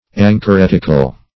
Search Result for " anchoretical" : The Collaborative International Dictionary of English v.0.48: Anchoretic \An`cho*ret"ic\, Anchoretical \An`cho*ret"ic*al\, a. [Cf. Gr.
anchoretical.mp3